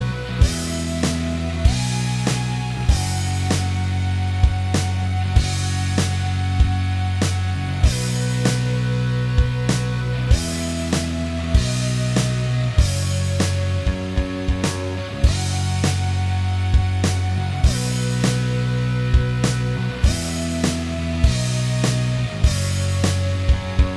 Minus Lead Guitar Rock 4:57 Buy £1.50